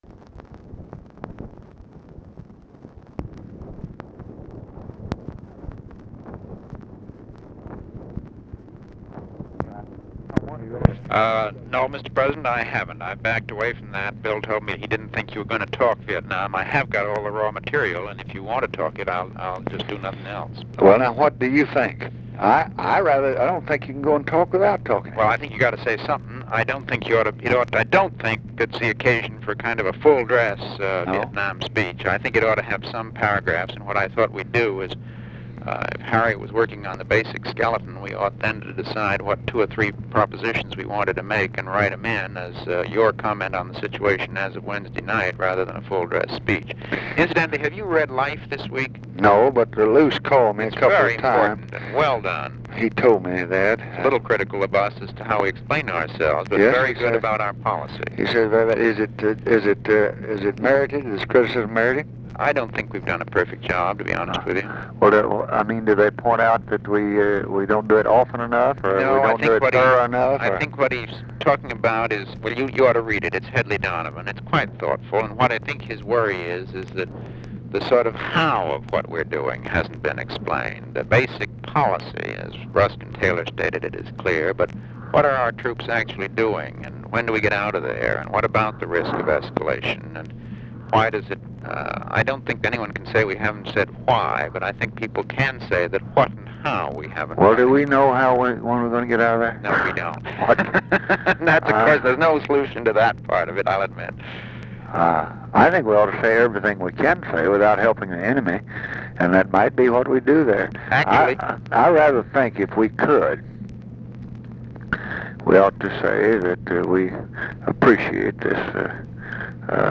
Conversation with MCGEORGE BUNDY, February 22, 1966
Secret White House Tapes